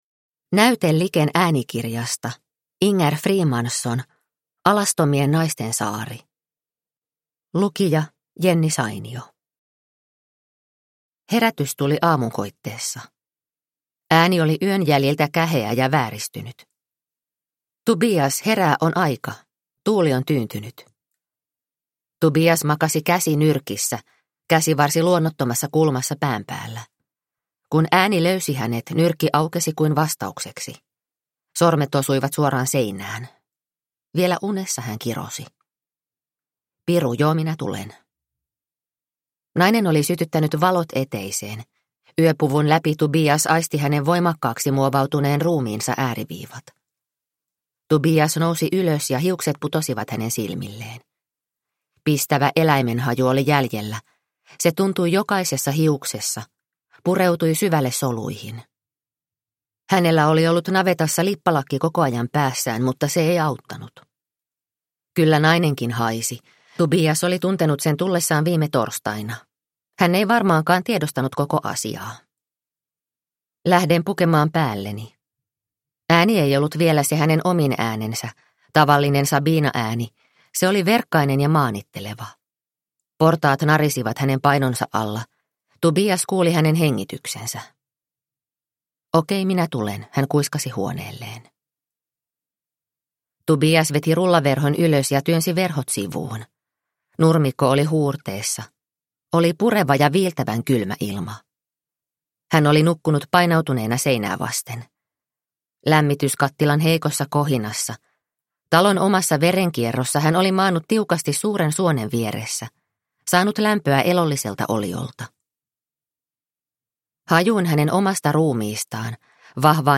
Alastomien naisten saari – Ljudbok – Laddas ner